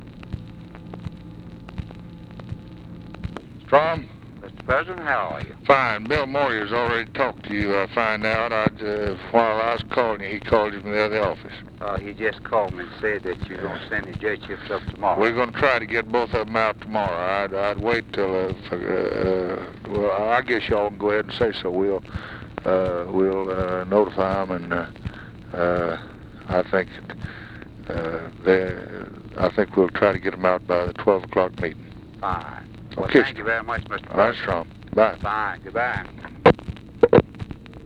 Conversation with STROM THURMOND, April 14, 1964
Secret White House Tapes